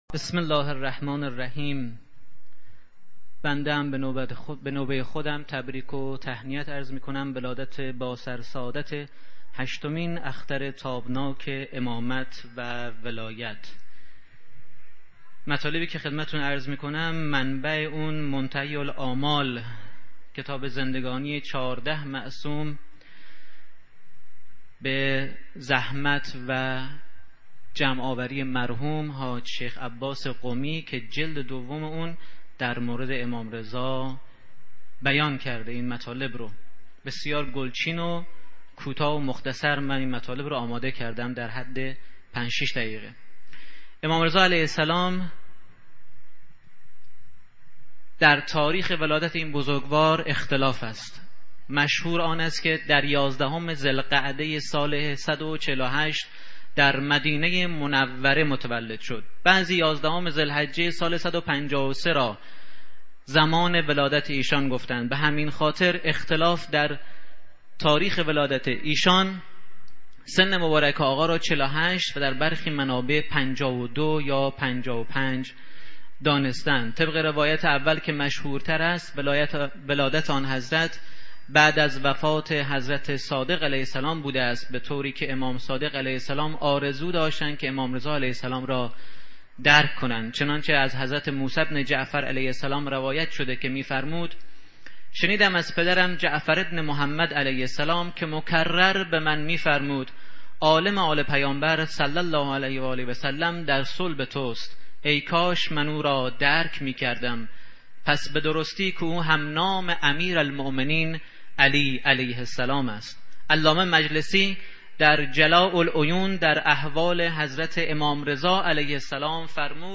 در این بخش می توانید فایل صوتی بخش های مختلف “دوازدهمین کرسی تلاوت و تفسیر قرآن کریم” شهرستان علی آباد کتول که در تاریخ ۳/شهریور ماه/۱۳۹۴ برگزار شد را مشاهده و دریافت نمایید.